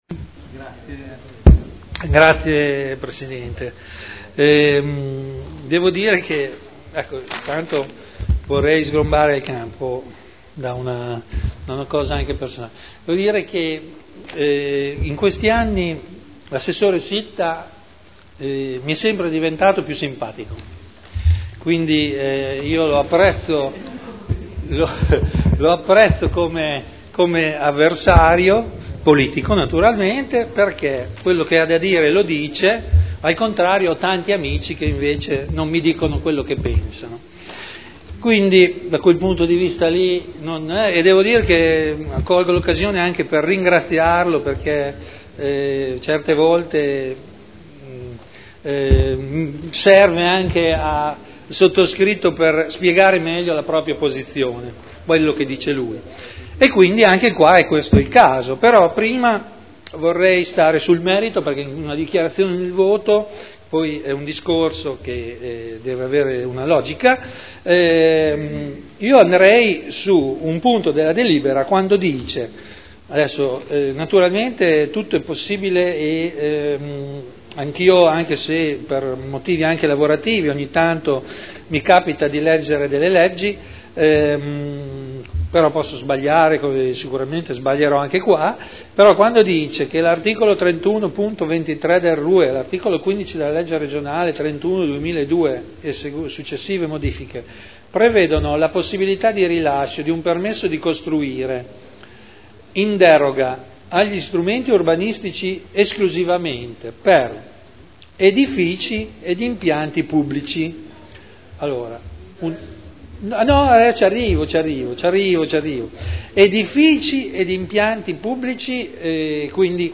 Seduta del 14 novembre Zona elementare n. 50 Area 13 di proprietà comunale - Parere favorevole all'attuazione delle previsioni urbanistiche dell'area con permesso di costruire convenzionato in deroga al Piano particolareggiato (Art. 31.23 RUE) Dichiarazioni di voto